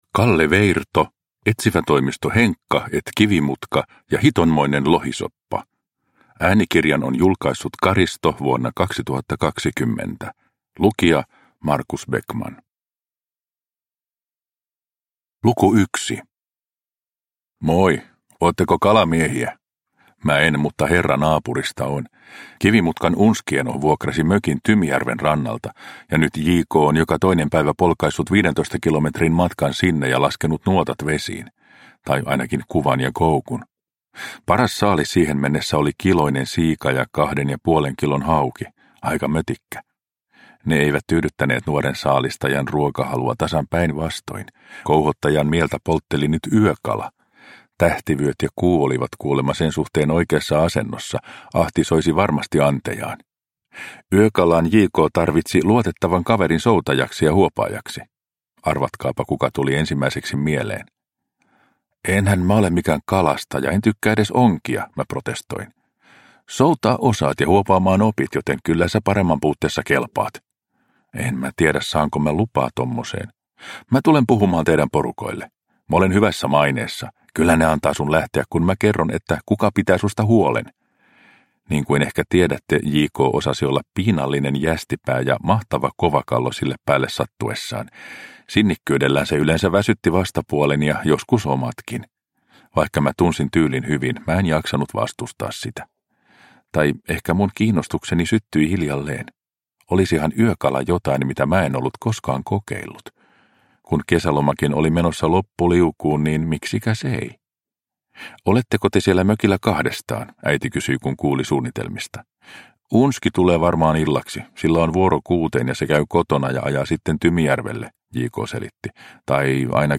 Etsivätoimisto Henkka & Kivimutka ja hitonmoinen lohisoppa – Ljudbok – Laddas ner